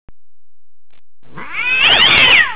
kitty.WAV